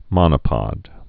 (mŏnə-pŏd)